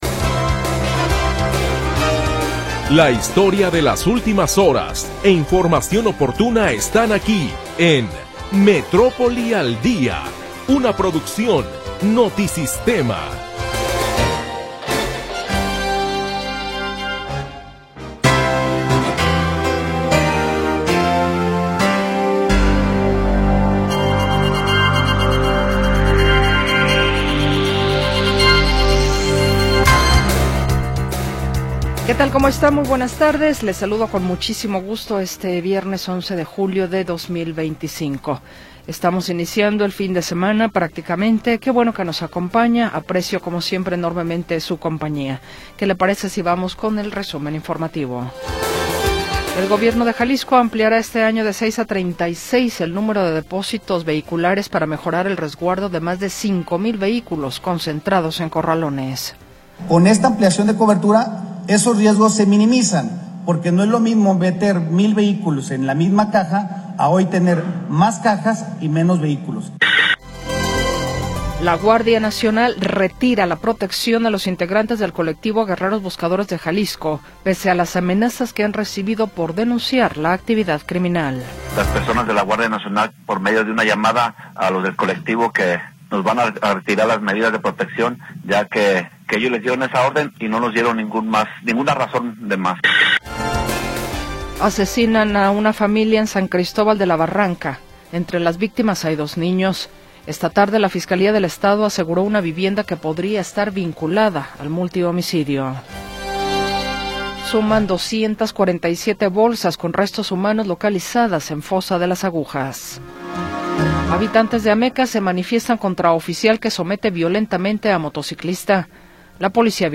Primera hora del programa transmitido el 11 de Julio de 2025.